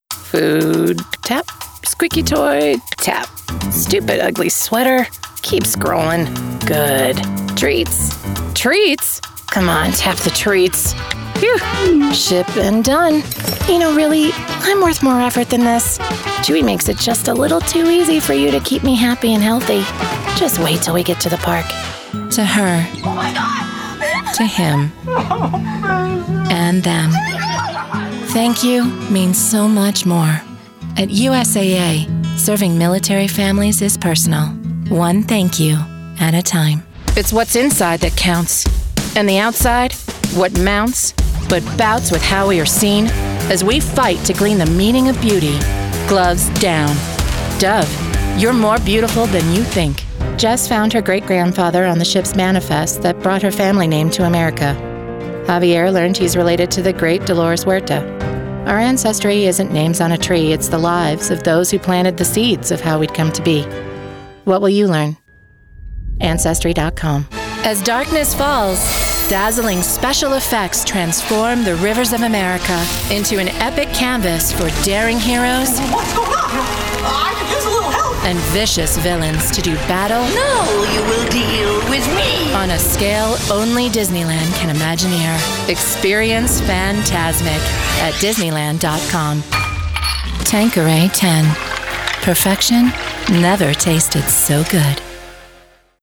North American Female Voiceactor
Commercial Demo
I take pride in crafting a voice recording that suits your needs using my professional home studio.